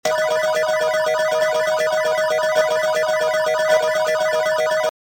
jackpot_spin.mp3